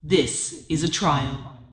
Subject description: Perhaps an extremely rare basic main battle tank with a female voice   Reply with quote  Mark this post and the followings unread
I am not a woman, this is using AI technology to replace my vocals with AI tones.